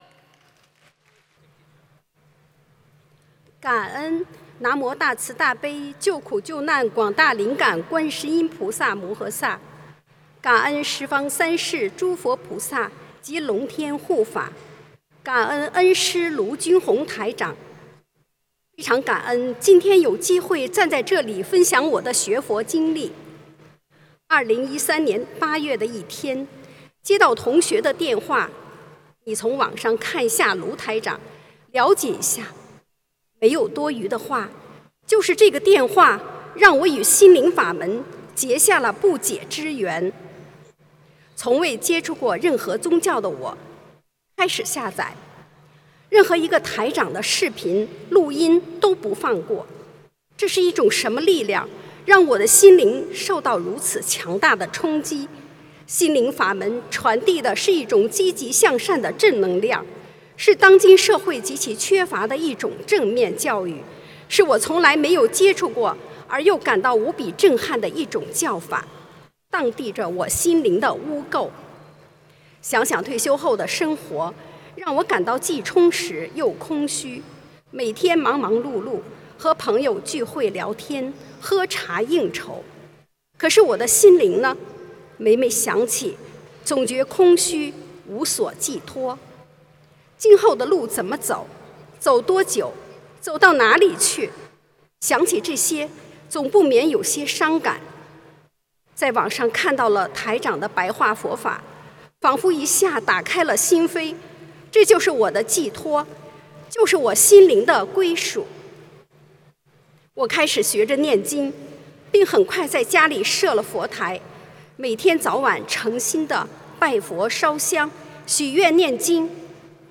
视频：102_学佛救度全家重病痊愈转危为安--2016年9月3日 加拿大温哥华 - 『同修分享视频』 -